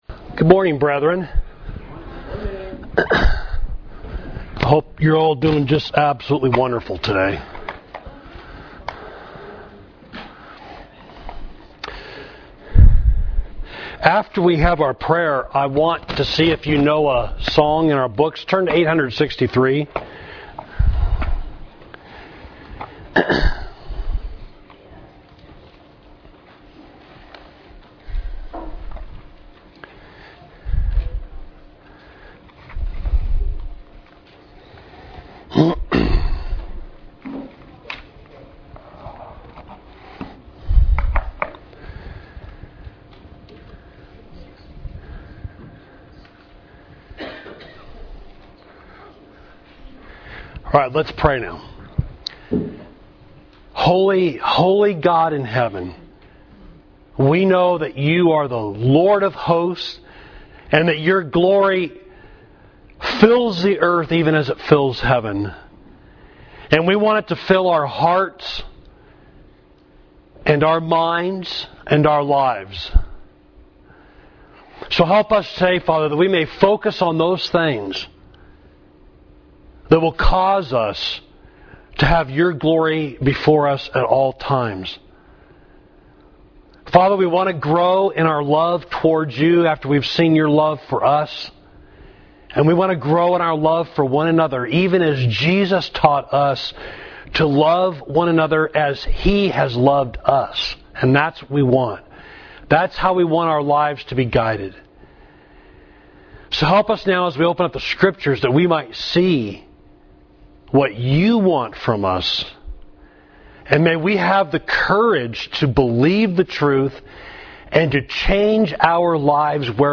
Class: What Are the Benefits of Church Membership? #2